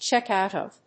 アクセントchéck out of…